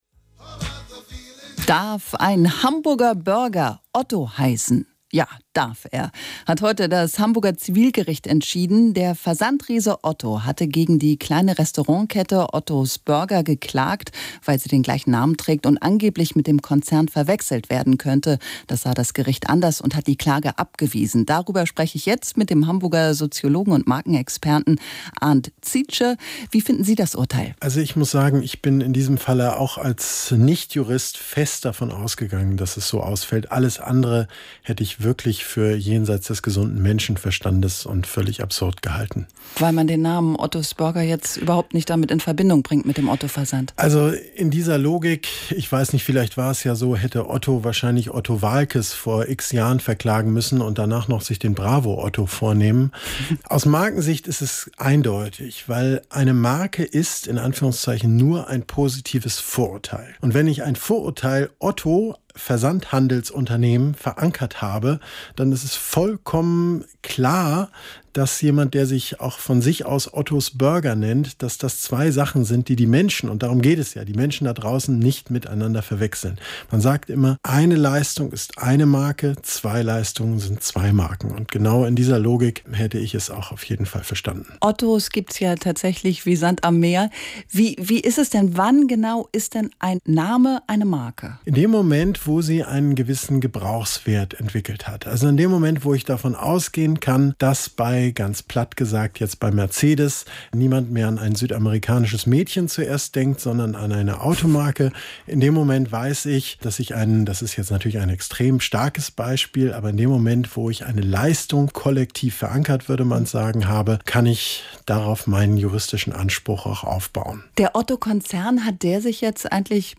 NDR-Interview: Streit um die Marke Otto… Burger gegen Versandhaus